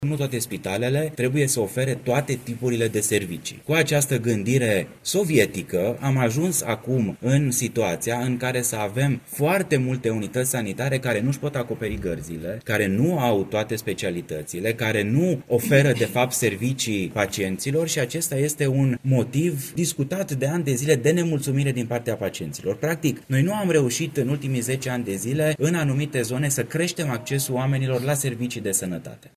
Ministrul Sănătății, Alexandru Rogobete, a anunțat la Timișoara că toate spitalele publice vor fi reclasificate, fără ca acest proces să implice închideri de unități.